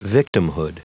Prononciation du mot victimhood en anglais (fichier audio)